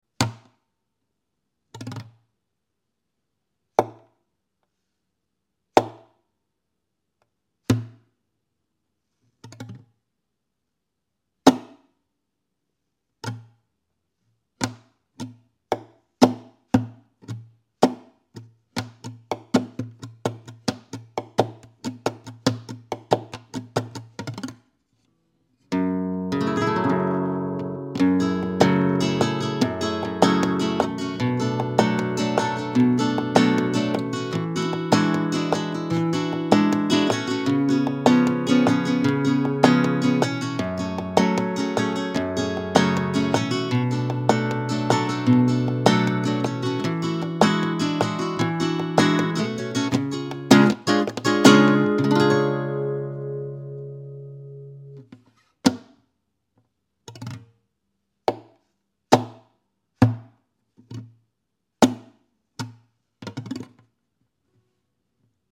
How To Play Slow Rumba Sound Effects Free Download